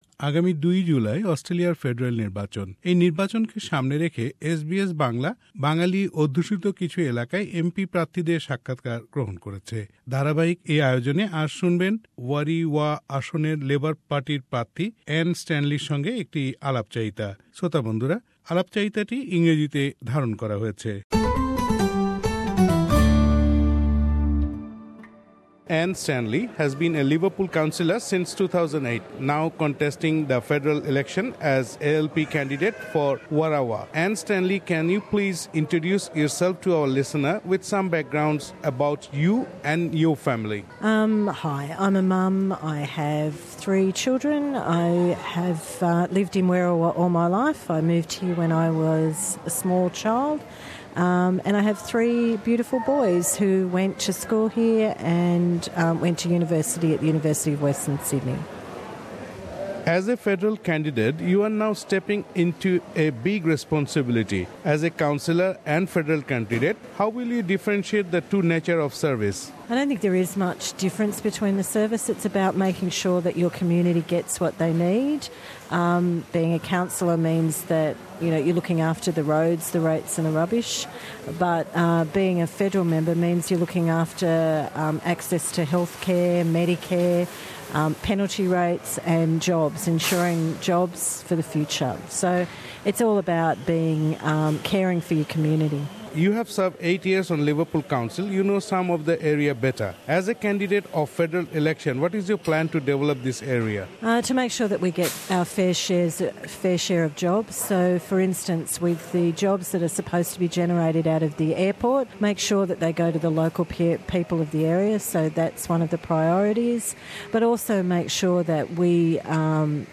Election 2016 :Interview with Anne Stanley